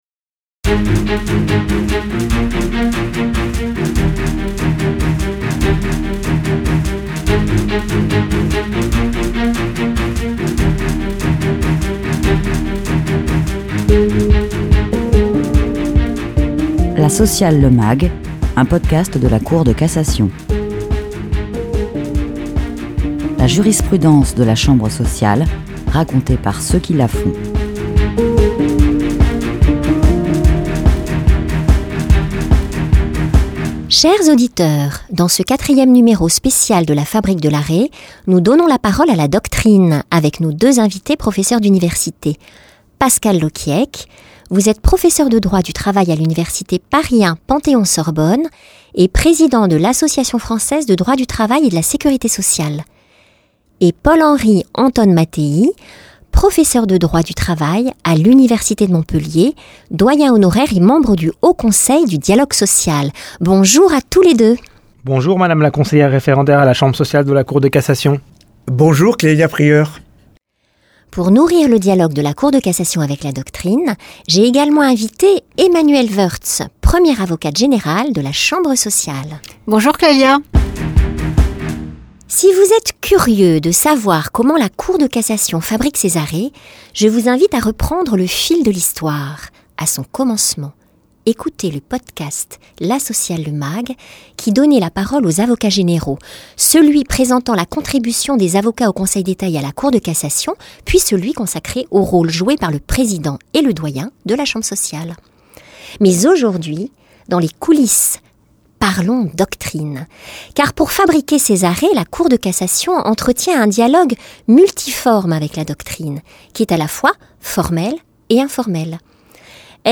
un documentaire